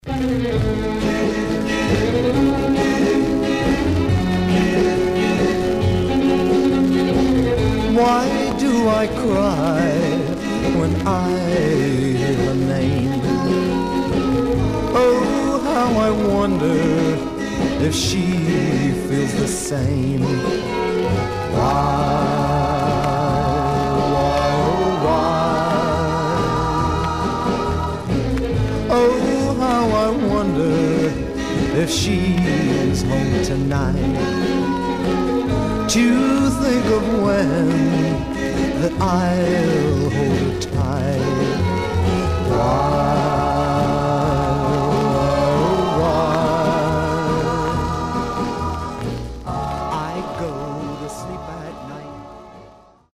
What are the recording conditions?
Much surface noise/wear Stereo/mono Mono